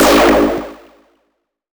spawn.ogg